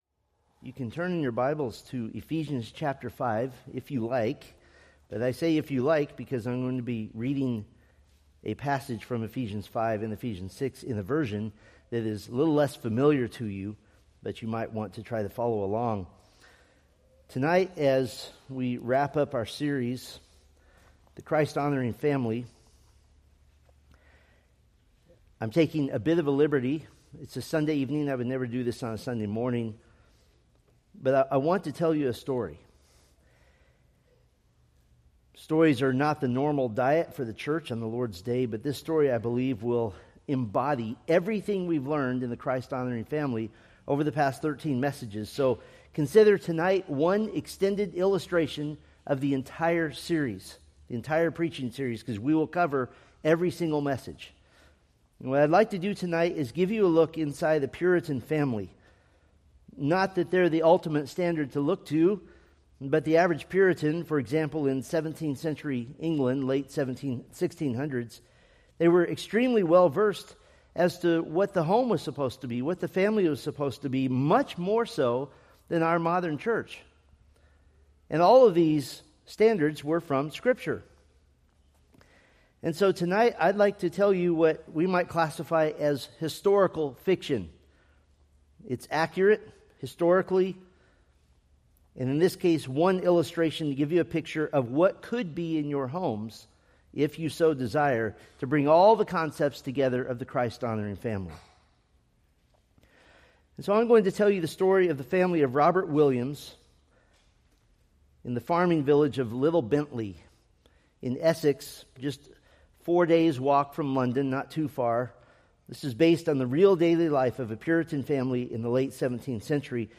Preached August 31, 2025 from Selected Scriptures